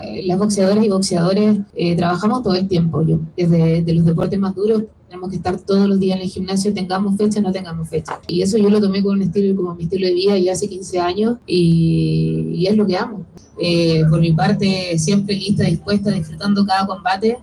La campeona valdiviana, en rueda de prensa habló sobre su preparación.